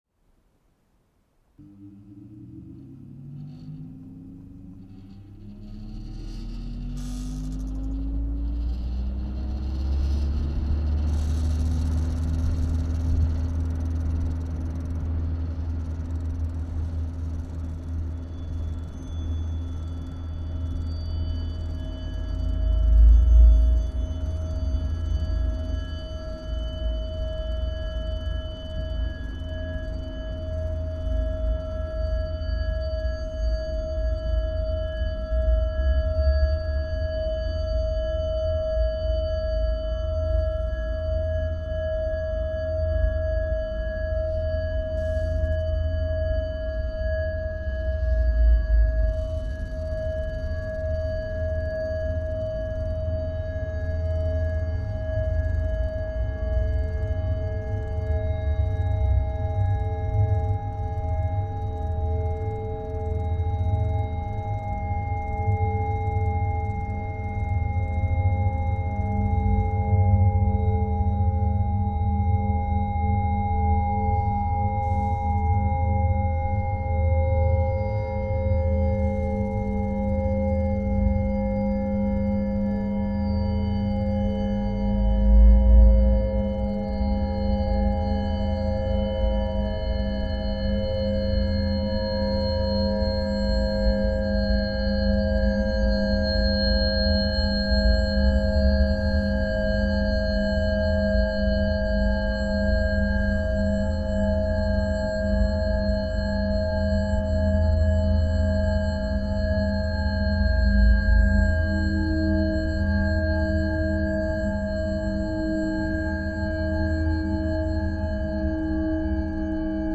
Obsolescent sounds meet obsolescent technology.